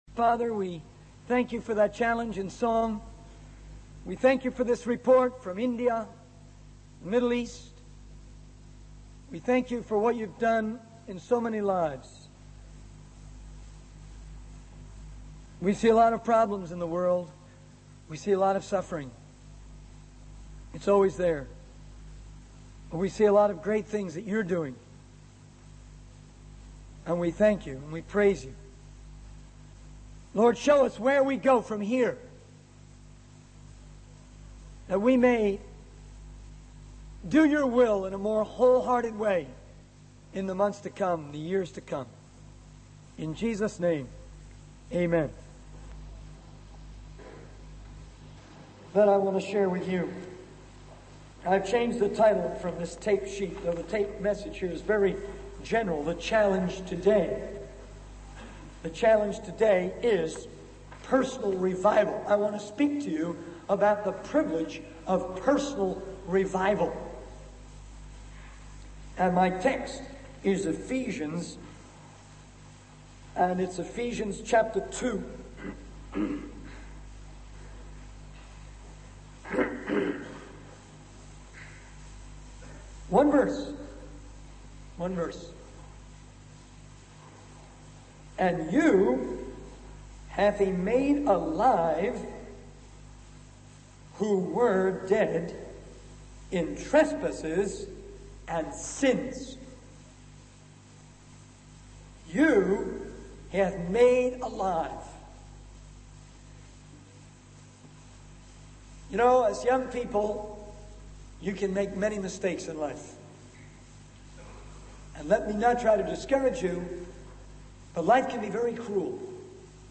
In this sermon, the speaker expresses his frustration with Christians who lack initiative and vision in spreading the word of God. He shares his personal experience of being a backslider before finding salvation and emphasizes the importance of personal revival. The speaker encourages the audience to make a commitment to move towards evangelism and to be filled with the Holy Spirit.